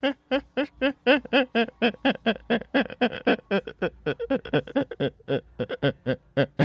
Risos